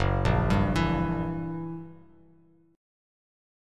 F#7b9 chord